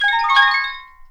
magma_conduits_chime01.ogg